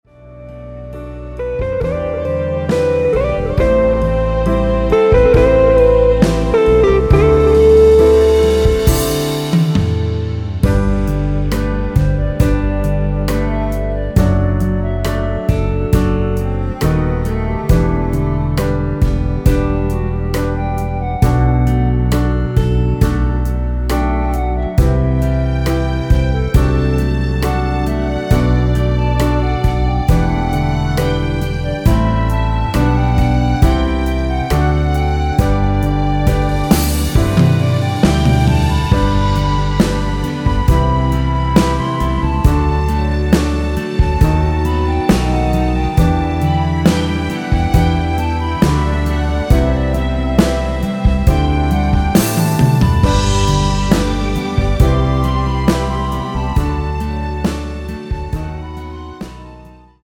원키에서(-1)내린 멜로디 포함된 MR입니다.(미리듣기 확인)
앞부분30초, 뒷부분30초씩 편집해서 올려 드리고 있습니다.
곡명 옆 (-1)은 반음 내림, (+1)은 반음 올림 입니다.
(멜로디 MR)은 가이드 멜로디가 포함된 MR 입니다.